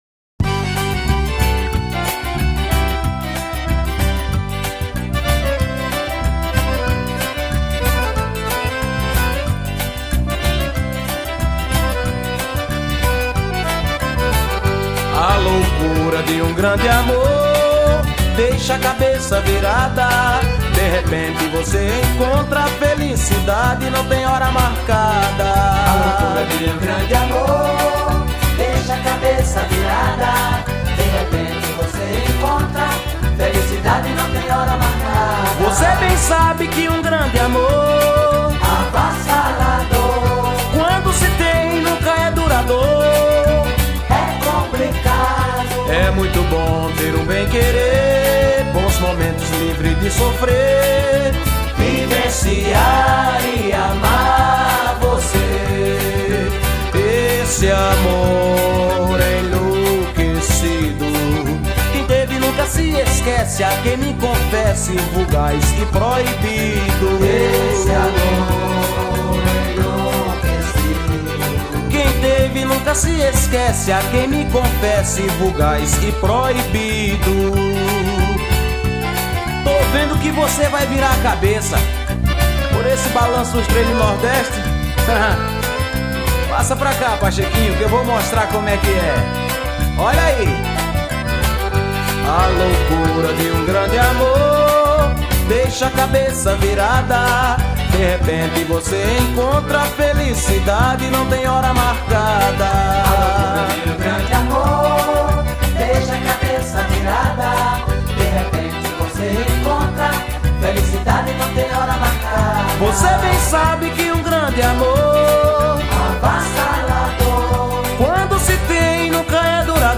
EstiloForró